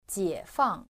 • jiěfàng